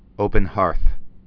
(ōpən-härth)